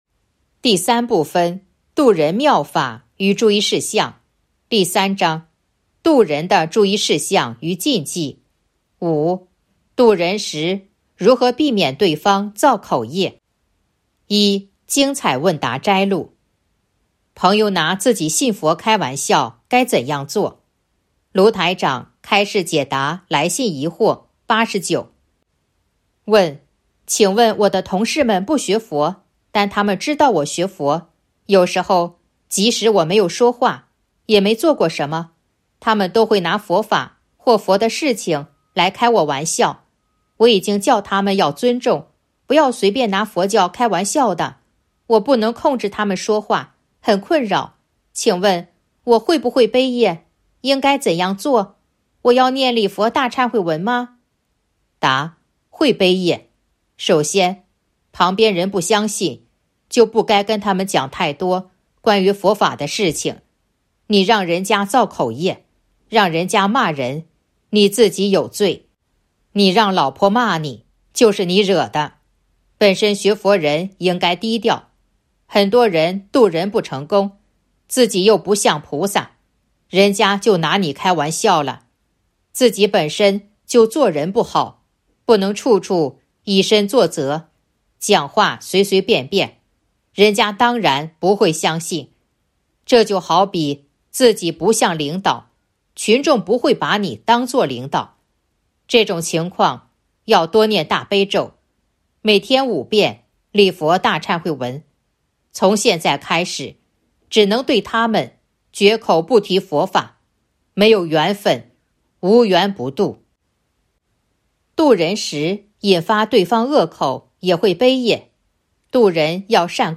058.1. 精彩问答摘录《弘法度人手册》【有声书】 - 弘法度人手册 百花齐放